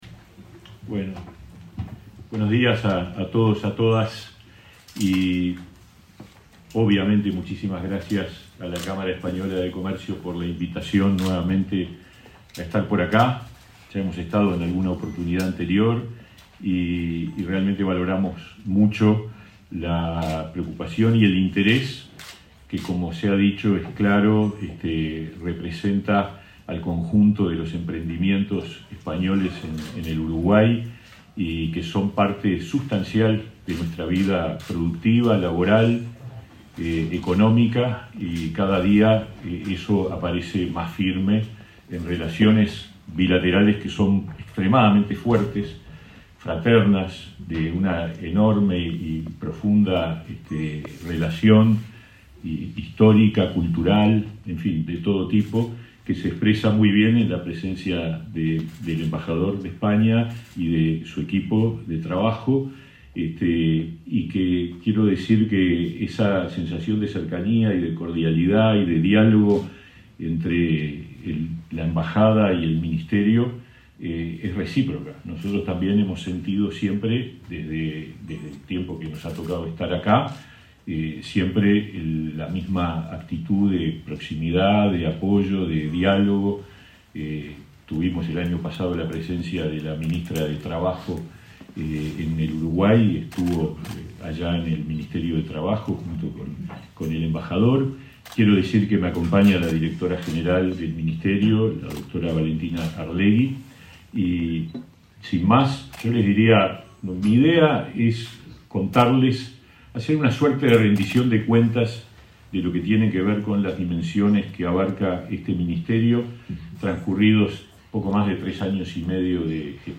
Disertación del ministro de Trabajo, Pablo Mieres
Este miércoles 25, el ministro de Trabajo, Pablo Mieres, disertó en un desayuno de consulta de la Cámara Española.